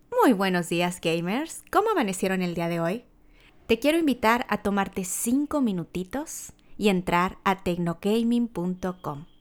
Si bien tiene diferentes funciones de uso, probamos en grabar la voz obteniendo los siguientes resultados:
Además, no se perciben los ruidos provenientes de otras partes.
MAONO-AU-PM422-Prueba-de-Voz-3.wav